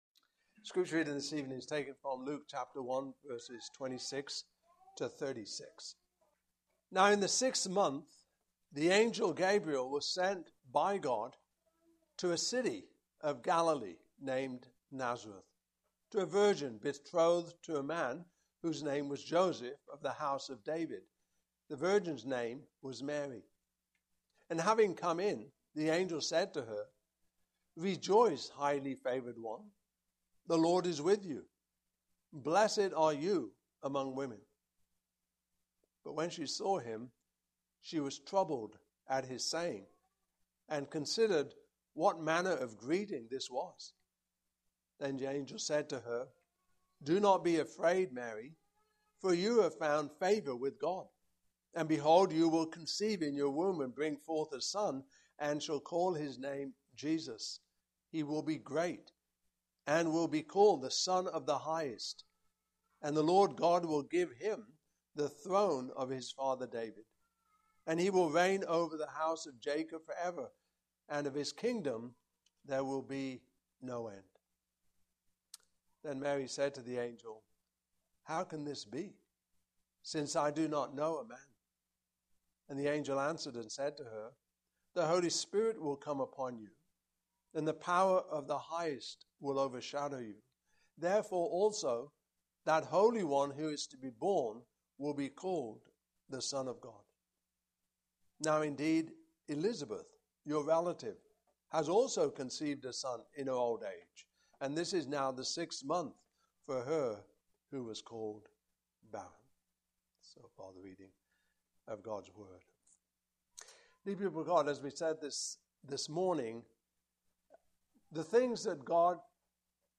Advent 2024 Passage: Luke 1:26-36 Service Type: Evening Service « Certainty of the Gospel Christ